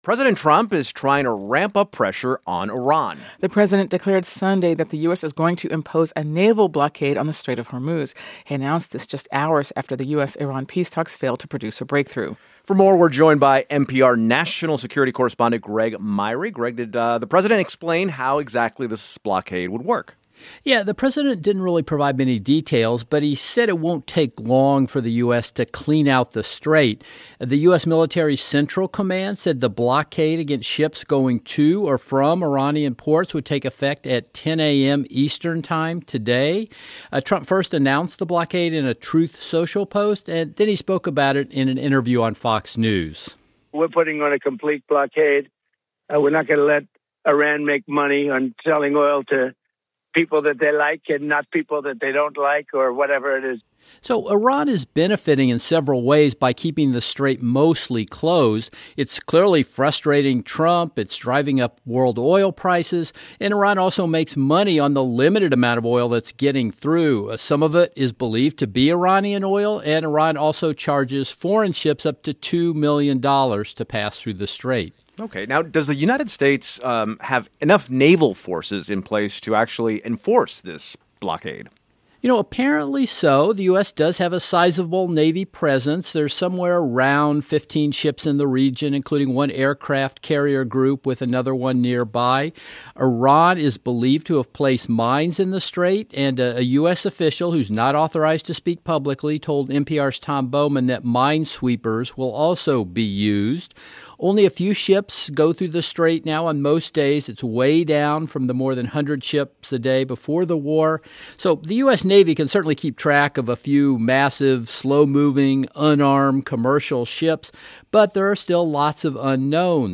Morning news brief